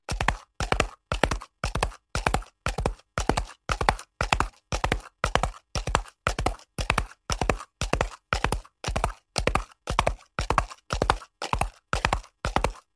Download Horse Running sound effect for free.
Horse Running